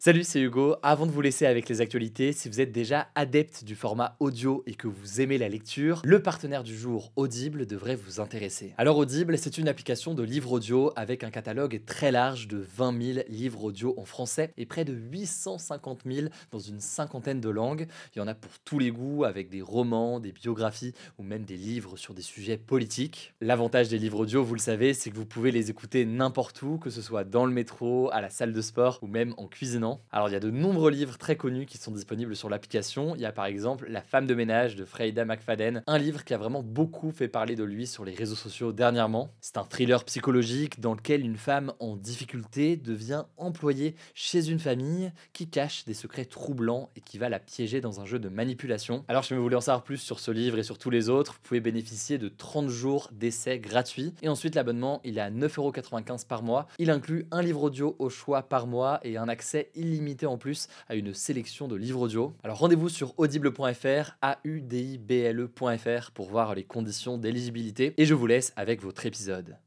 Le volet digital s’appuie sur un format host-read : le podcasteur présente lui-même son coup de cœur en pré-roll sur ses épisodes.
Chaque mois, l’un des deux podcasteurs sélectionne un livre audio et l’introduit par un message personnel, d’environ 45″, avant le contenu principal de son émission.